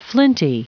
Prononciation du mot flinty en anglais (fichier audio)
Prononciation du mot : flinty